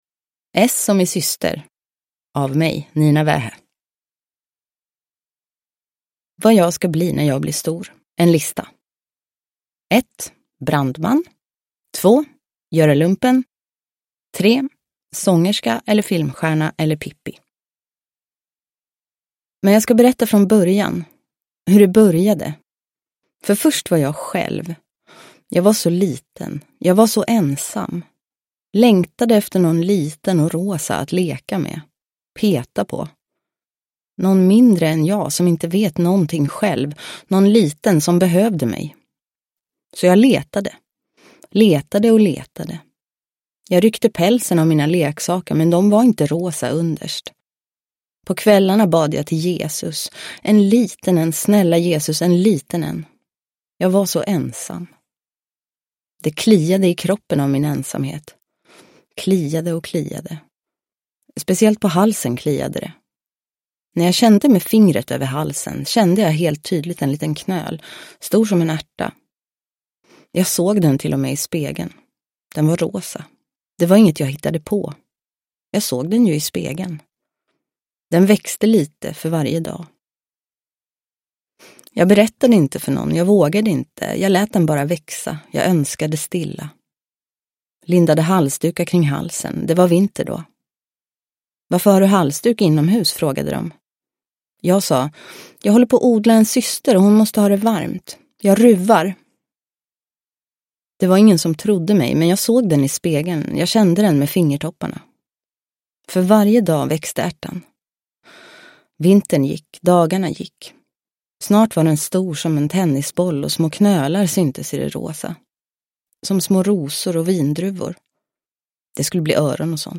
S som i syster – Ljudbok – Laddas ner
Uppläsare: Nina Wähä